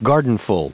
Prononciation du mot gardenful en anglais (fichier audio)
Prononciation du mot : gardenful